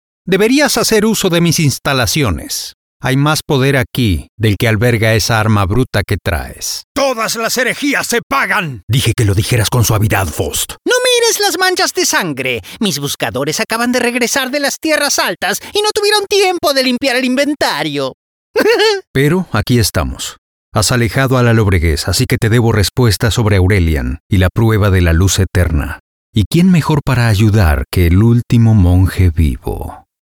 Video Games
Professional Home Studio; Isolated floor and acoustic treatment.
Baritone
AnnouncerClownCommentatorFatherProfessorStoryteller